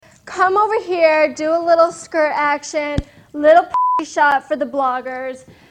Tags: Ke$ha Sound check Ke$ha Soundcheck Ke$ha clips Ke$ha Soundcheck c;lips Comedian